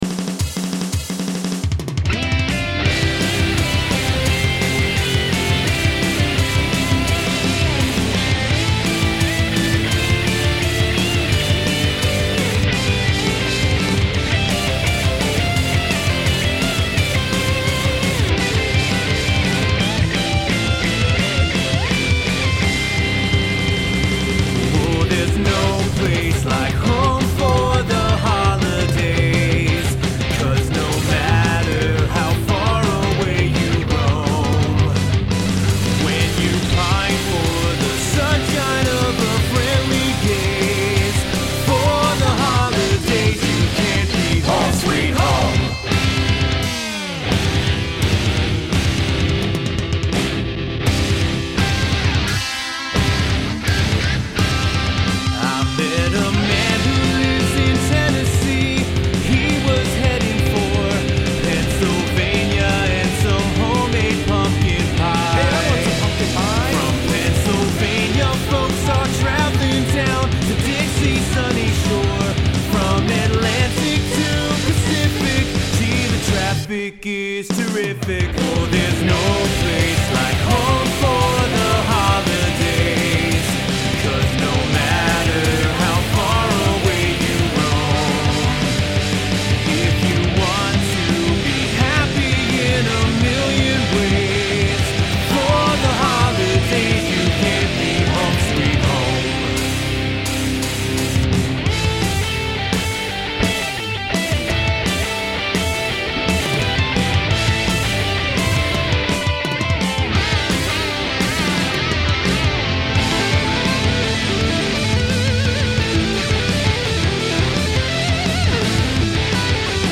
(Male lead and female backing vocals).
The vocals are well-balanced with each other in this mix.